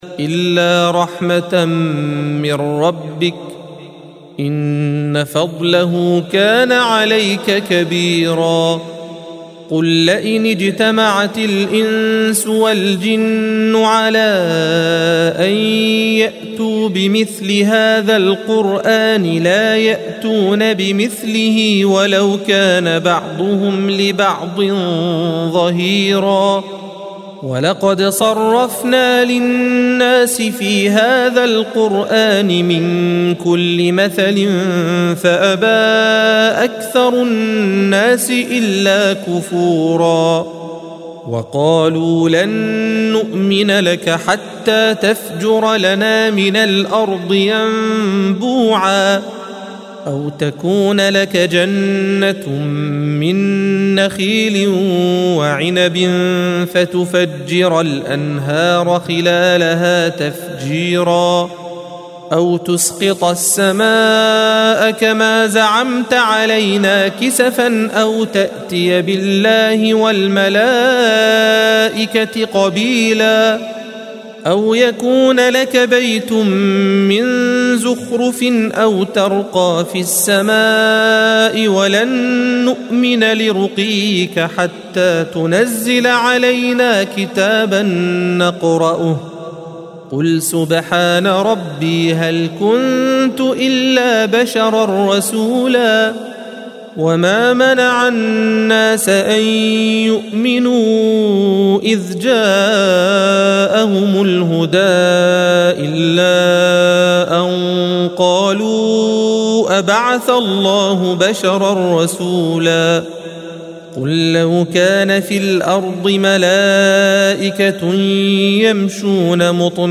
الصفحة 291 - القارئ